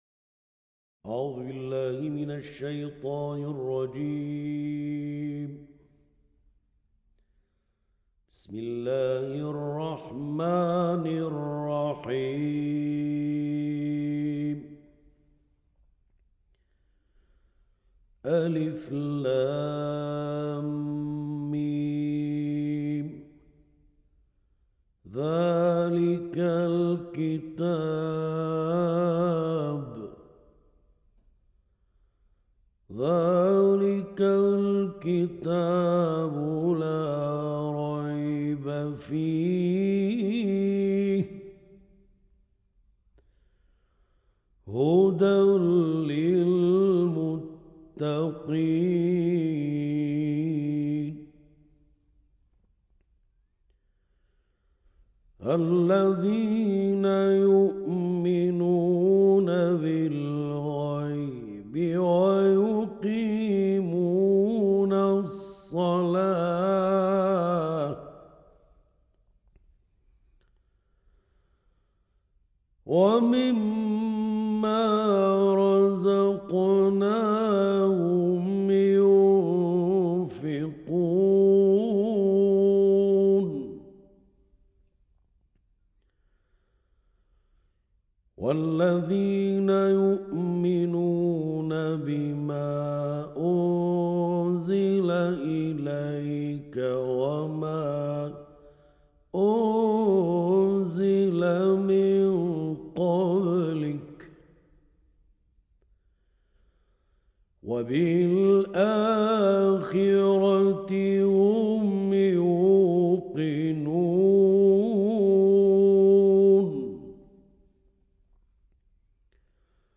而最引人注目的是乐队中“卡龙”的演奏者（卡龙，即波斯的桑图尔，东欧的钦巴龙， 传入中国后演变成扬琴）。